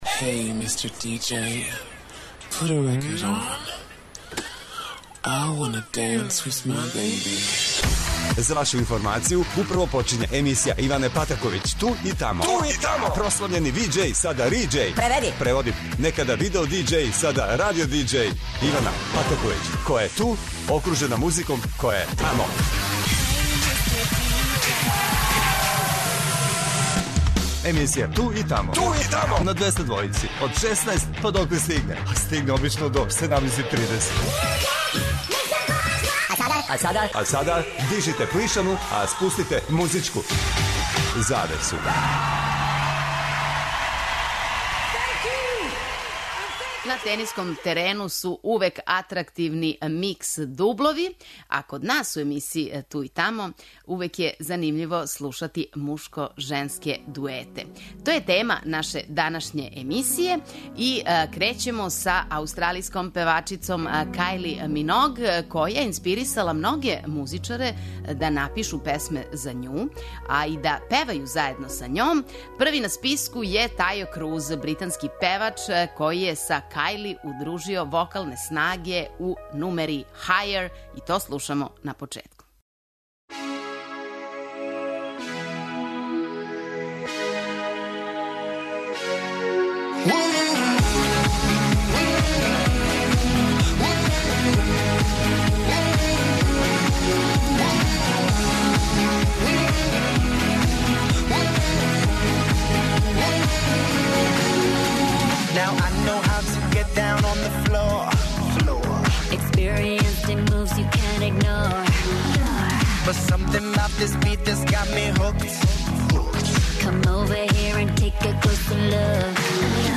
На тениском терену су увек атрактивни мешовити дублови, а на музичкој сцени - мешовити дуети које слушамо у новој емисији 'Ту и тамо'.